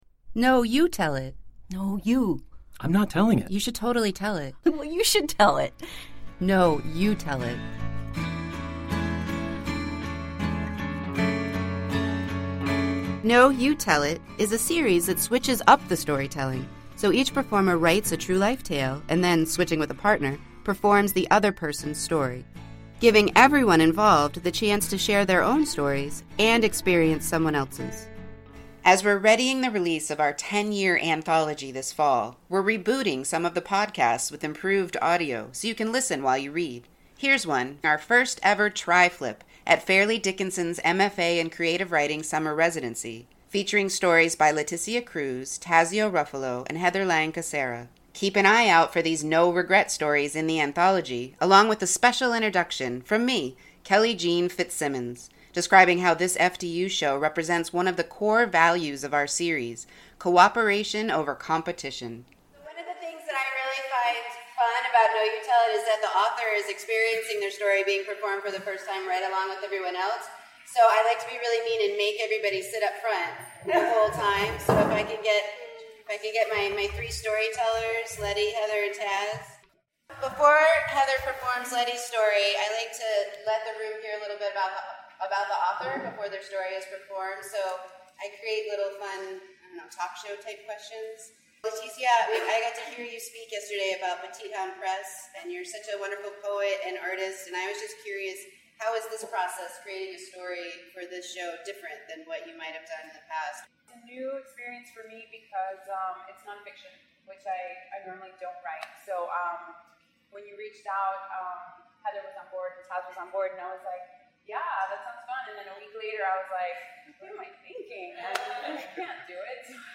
As we’re readying the release of our ten-year anthology this fall from Palm Circle Press, we’re rebooting some of the podcasts with improved audio so you can listen while you read.